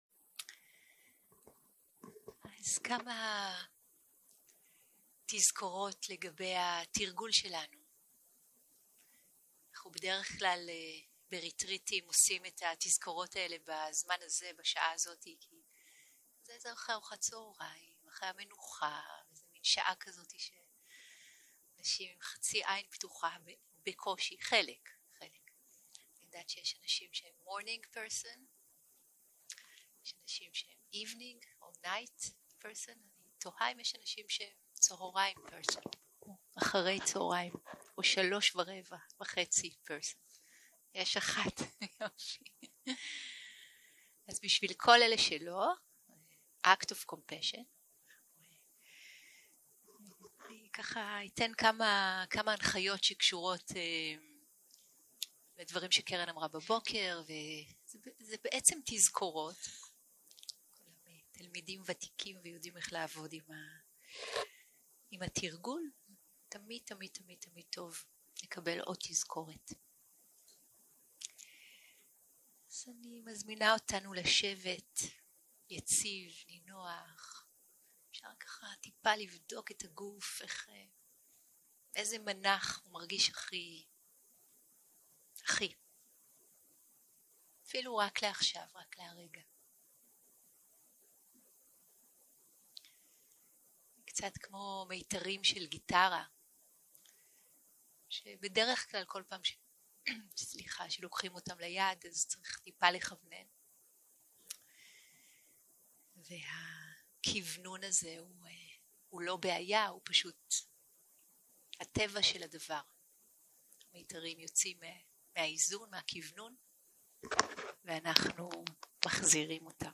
יום 2 - הקלטה 4 - צהרים - מדיטציה מונחית בשלושה שלבים - נוכחות בגוף, הרפייה והתרווחות 0:00 0:00 סוג ההקלטה: Dharma type: Guided meditation שפת ההקלטה: Dharma talk language: Hebrew